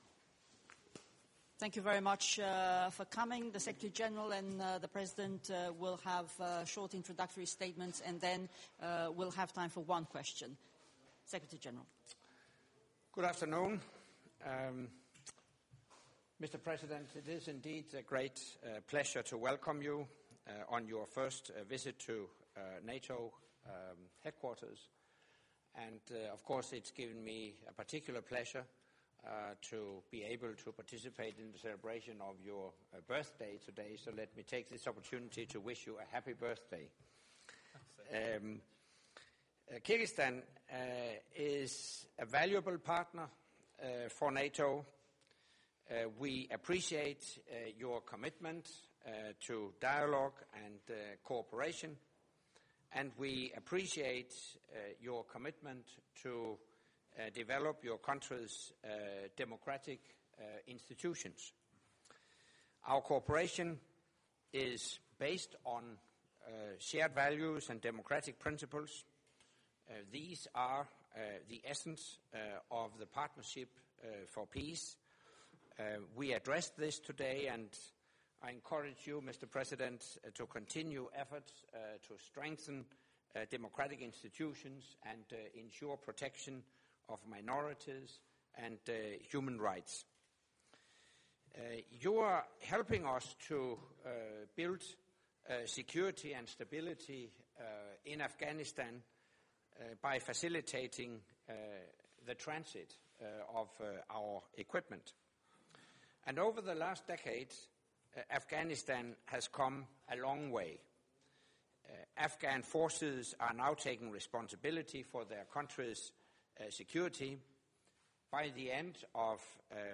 Joint Press Point with NATO Secretary General Anders Fogh Rasmussen and the President of the Kyrgyz Republic, Mr. Almazbek Atambayev